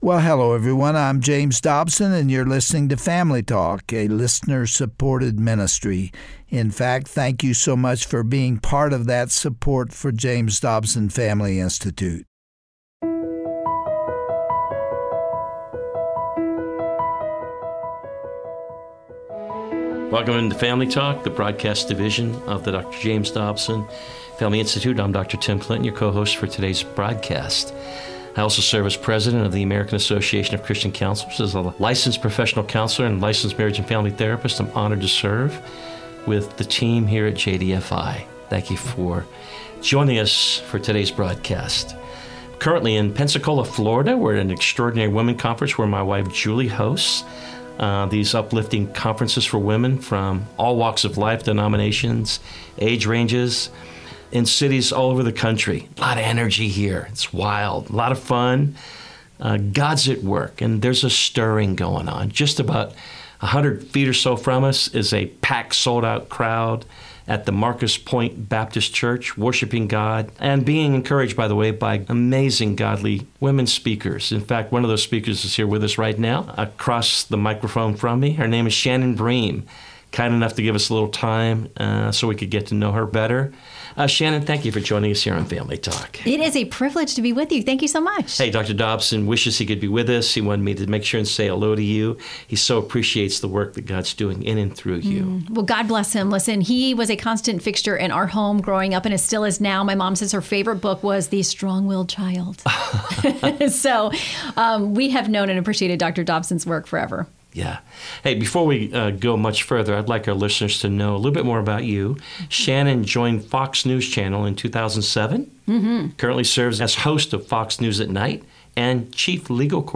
Guest(s):Shannon Bream